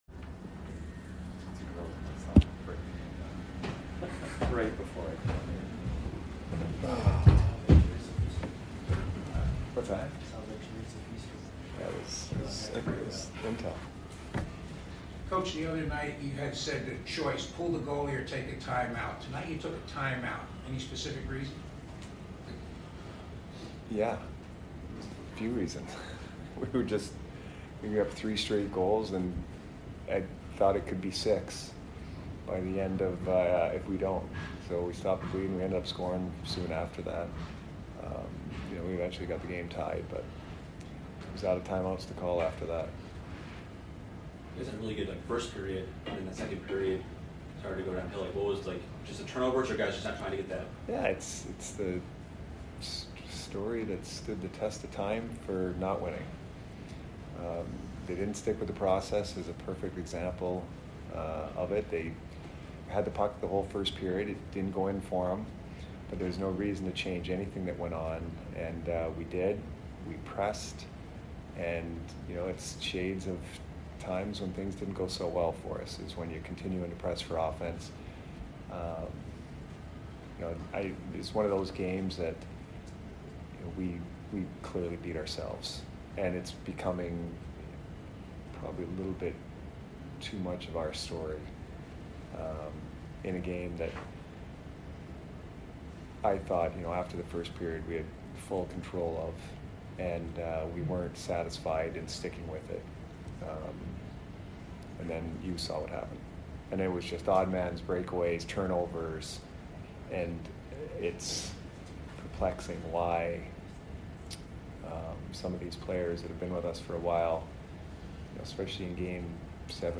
Head Coach Jon Cooper Post Game Vs DET 4 - 19 - 22